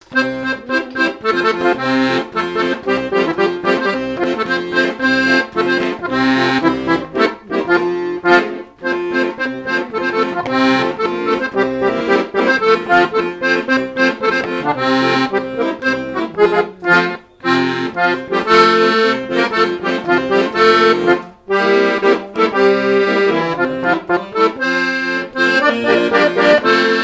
danse : quadrille : pastourelle
airs de danses issus de groupes folkloriques locaux
Pièce musicale inédite